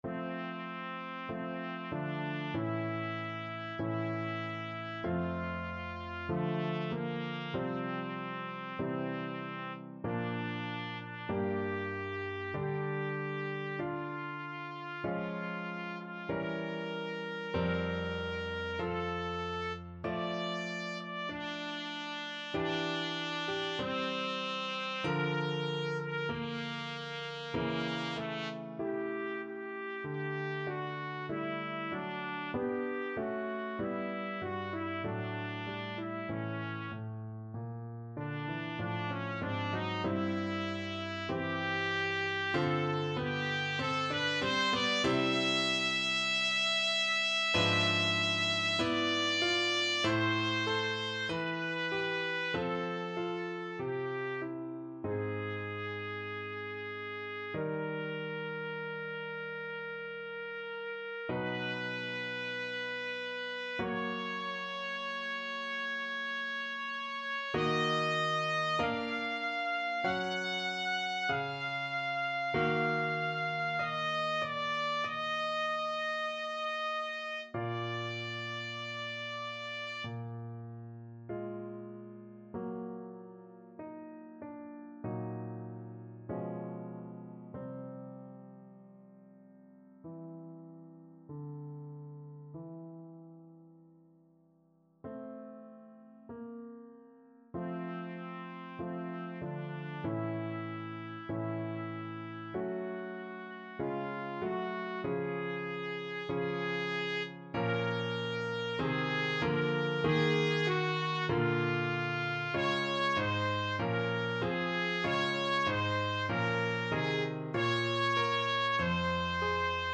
Classical Bruckner, Anton Ave Maria, WAB 7 Trumpet version
Trumpet
F major (Sounding Pitch) G major (Trumpet in Bb) (View more F major Music for Trumpet )
2/2 (View more 2/2 Music)
~ = 96 Alla breve. Weihevoll.
F4-F#6
Classical (View more Classical Trumpet Music)